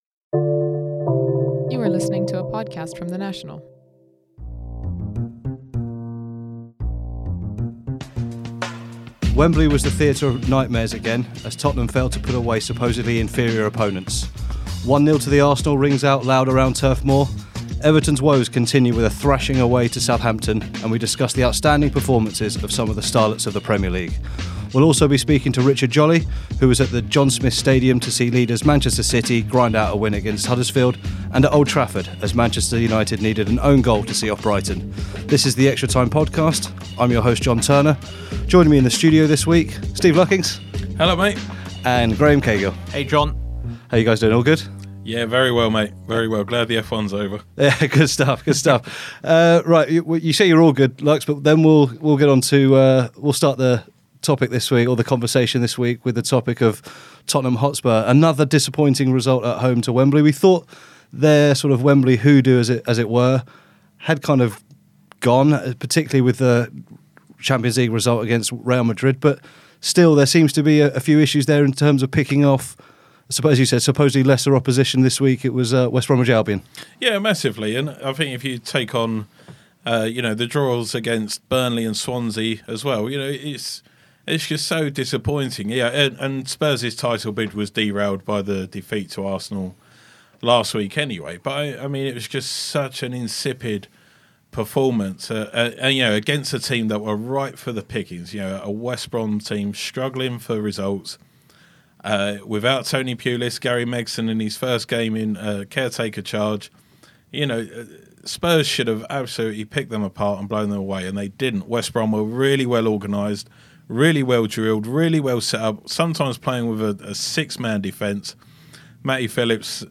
football correspondent